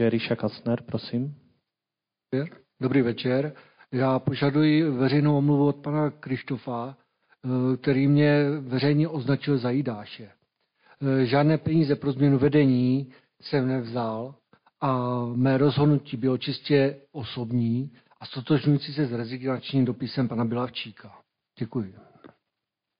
Dozvuky přeběhlictví na 15. ZM 2024
Zastupitel Kastner se domáhal omluvy od zastupitele Kryštofa za údajné nařčení: „Jidáš!“